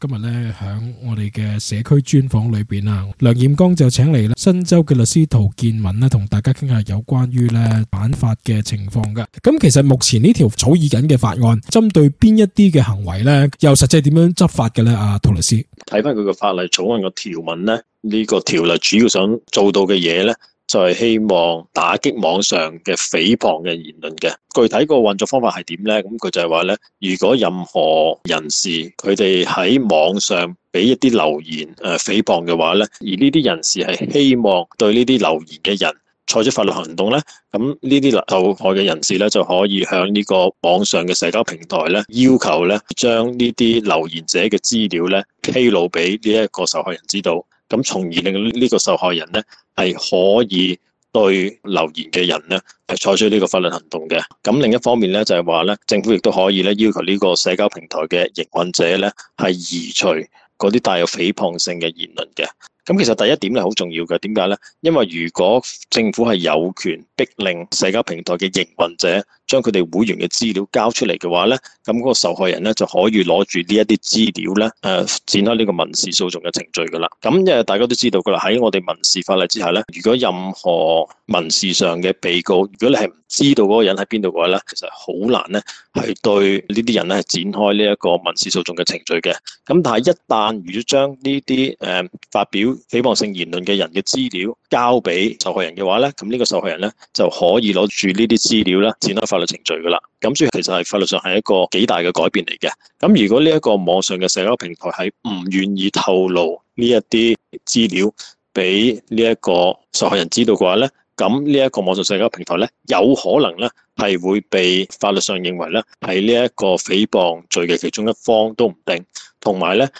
interview_is_federals_new_potential_legistry_effective_on_fighting_online_trooling_activities_podcast_1.mp3